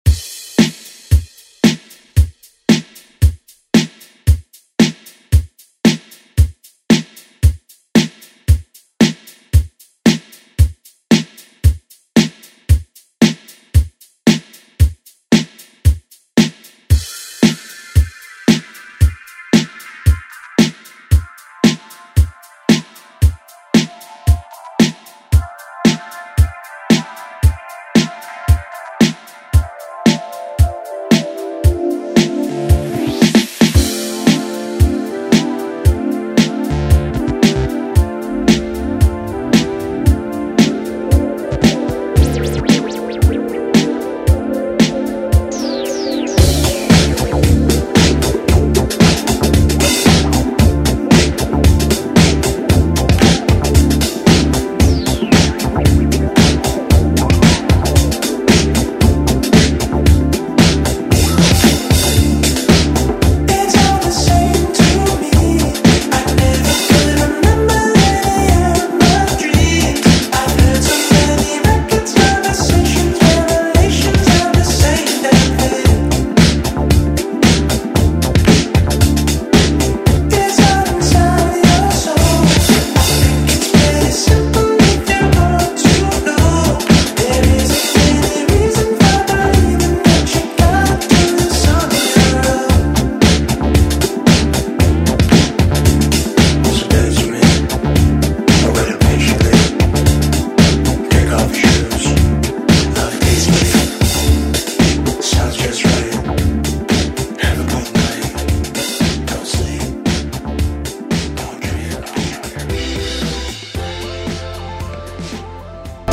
Throwback Electronic Pop Music Extended ReDrum Clean 126 bpm
Genres: 80's , RE-DRUM
Clean BPM: 126 Time